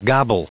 Prononciation du mot gobble en anglais (fichier audio)
Prononciation du mot : gobble